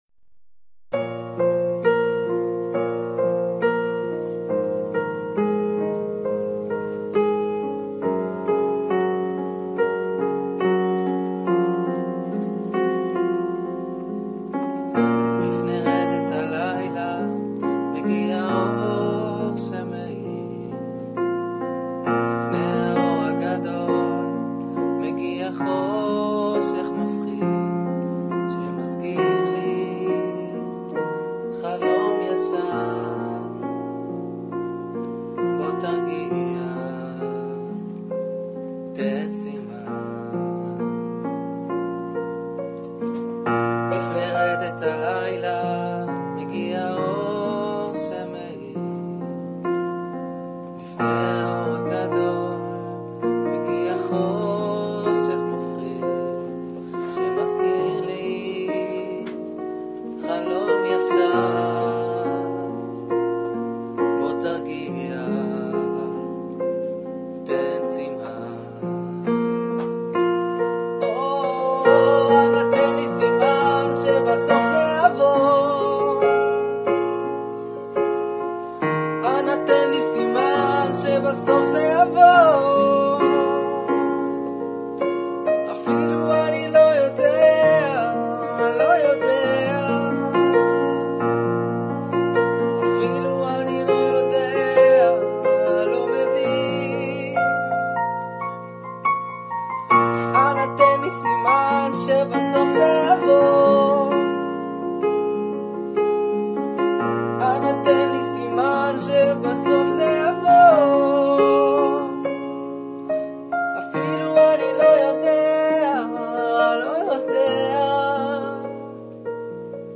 נגינה בפסנתר